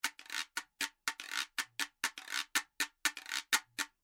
danzon guiro.mp3